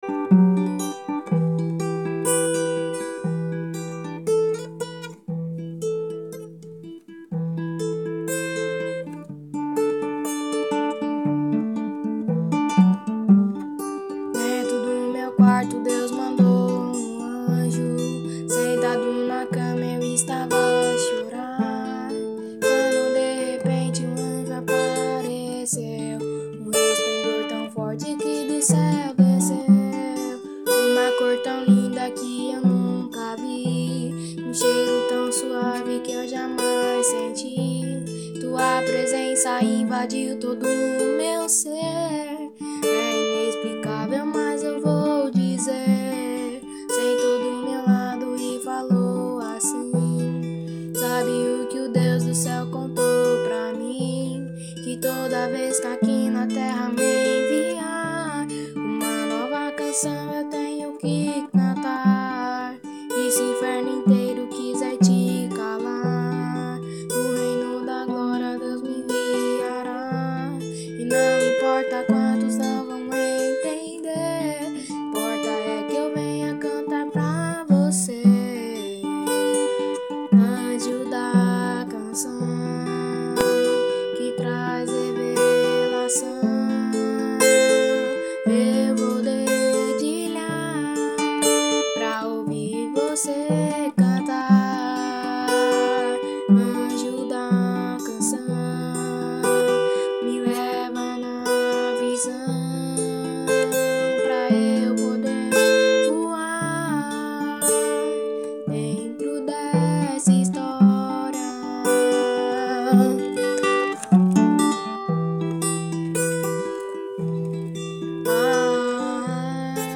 EstiloGuitar